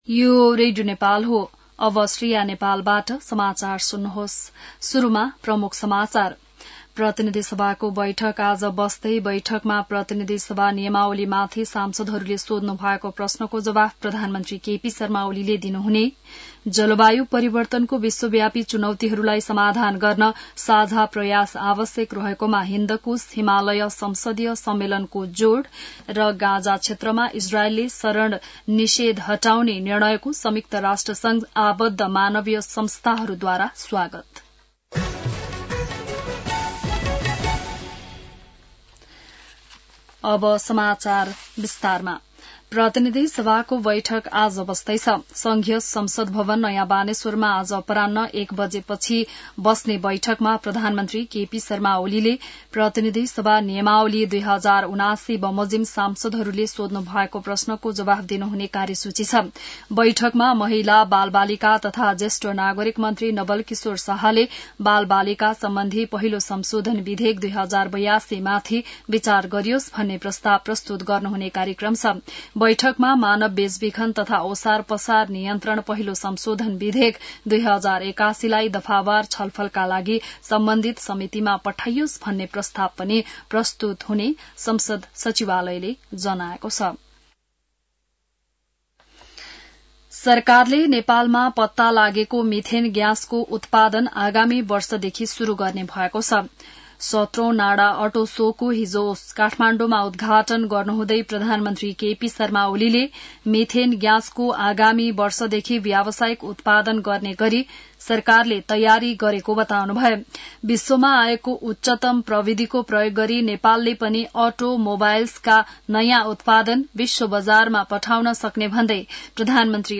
बिहान ९ बजेको नेपाली समाचार : ४ भदौ , २०८२